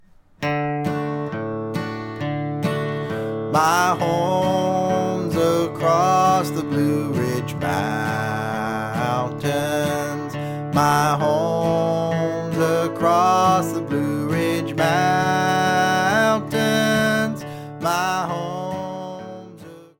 Slow version, chorus only (key of D)